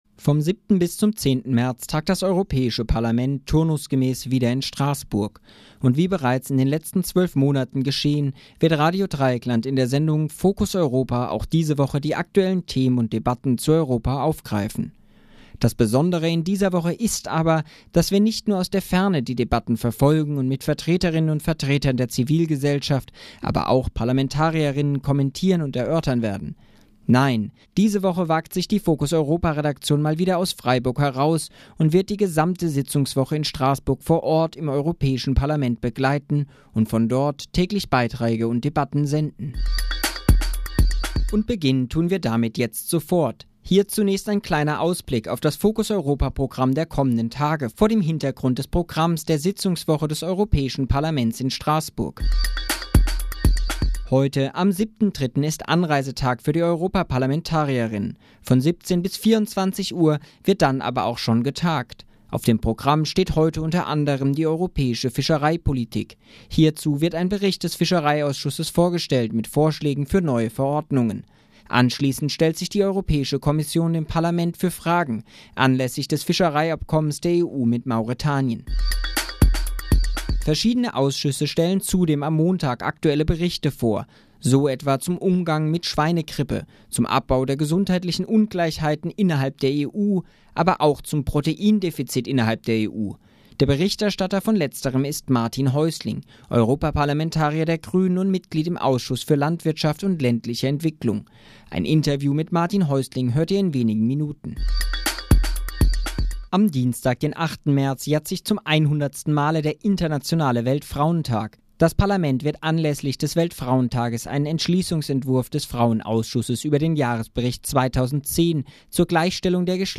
Focus Europa - direkt aus dem Europäischen Parlament in Strasbourg
Vom 07. bis zum 10. März senden wir unsere Beiträge direkt aus dem Europäischen Parlament in Straßbourg.